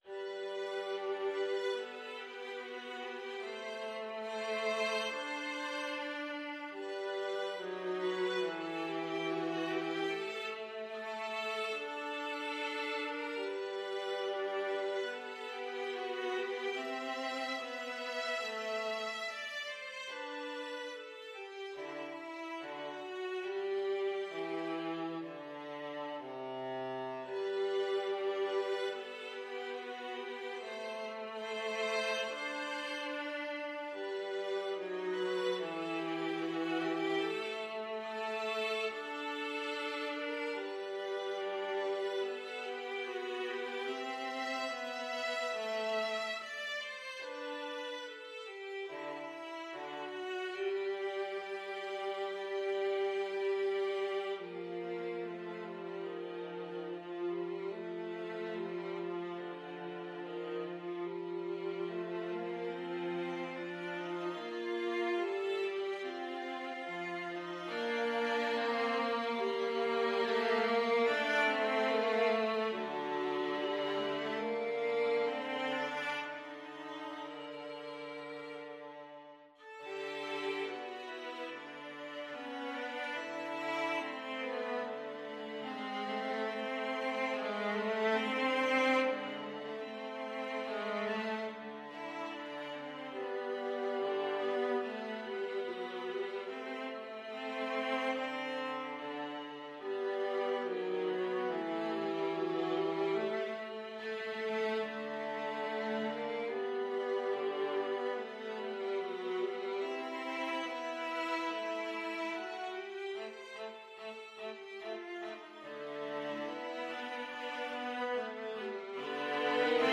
G major (Sounding Pitch) (View more G major Music for Viola Duet )
2/4 (View more 2/4 Music)
Andantino =72 (View more music marked Andantino)
Viola Duet  (View more Intermediate Viola Duet Music)
Classical (View more Classical Viola Duet Music)